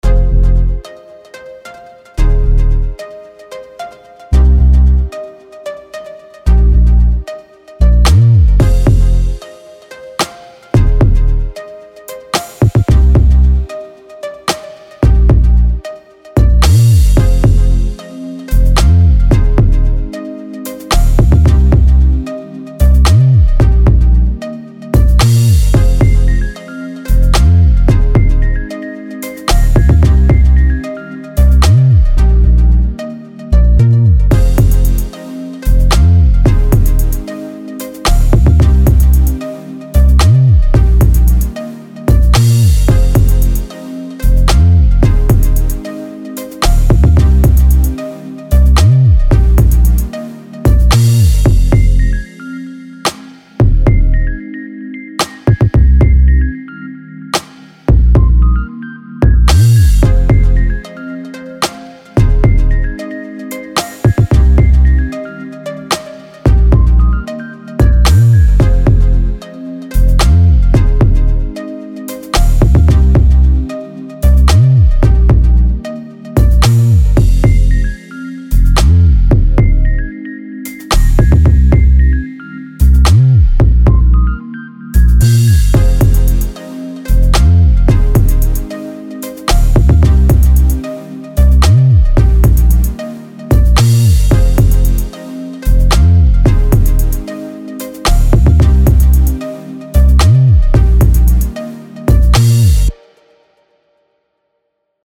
Demo song